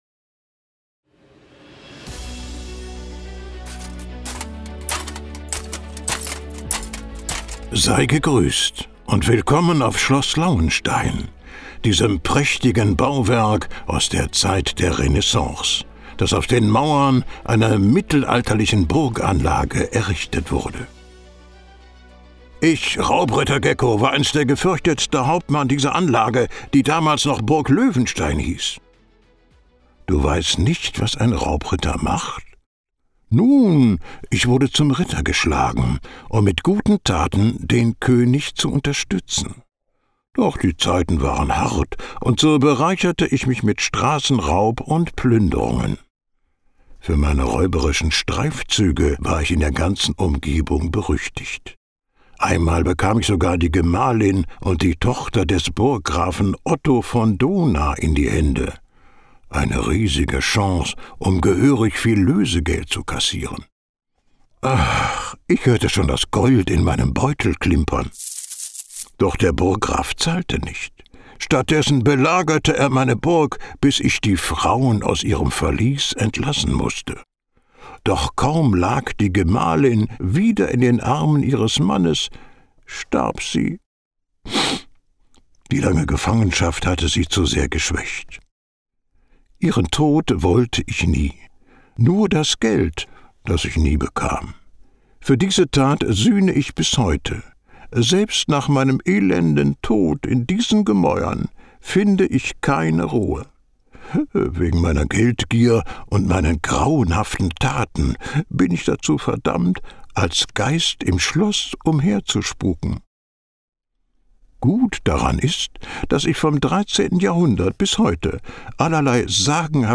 Entdecke die faszinierenden Geschichten von Schloss Lauenstein mit dem Audioguide von Raubritter Gecko und erlebe unvergessliche Abenteuer in der Region Sachsen.